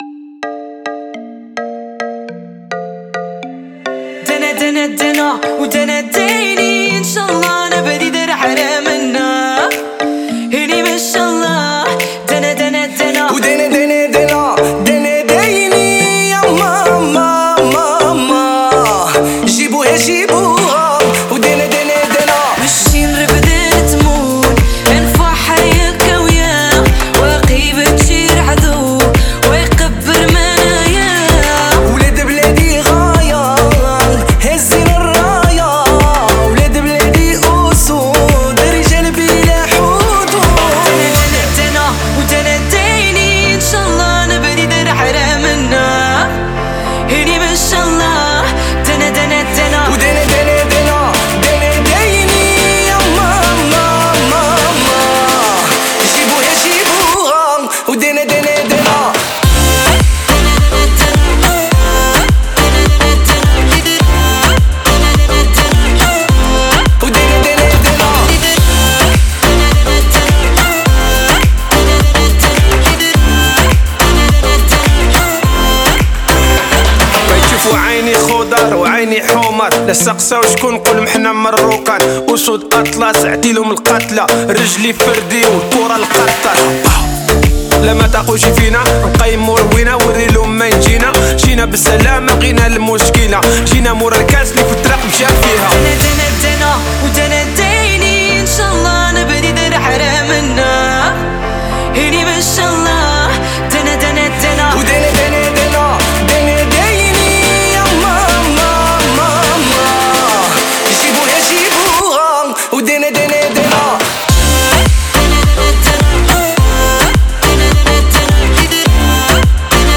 Похоже на специфическую флейту, но я не уверен.